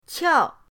qiao4.mp3